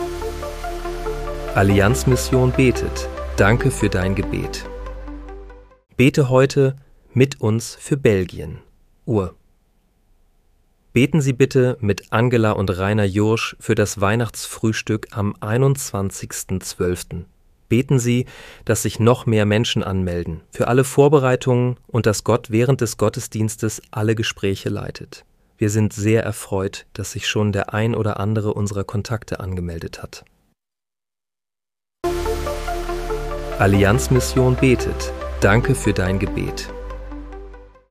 Bete am 15. Dezember 2025 mit uns für Belgien. (KI-generiert mit